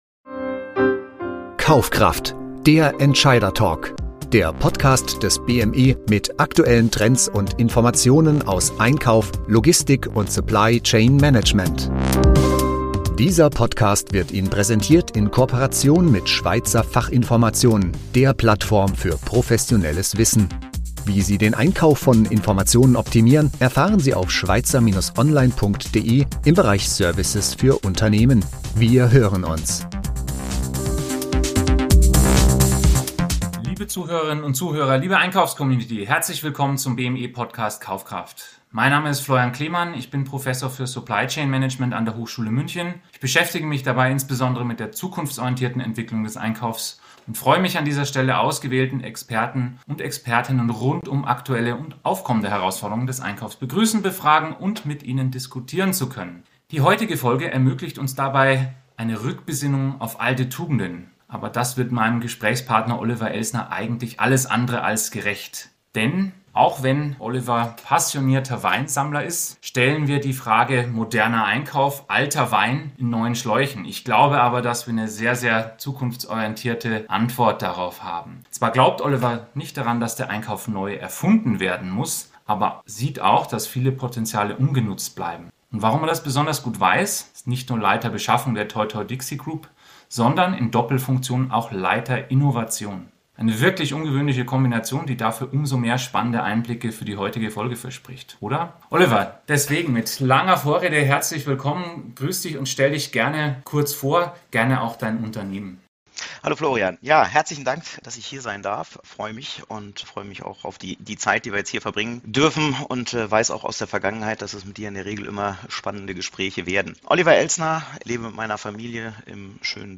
ein aufschlussreiches Gespräch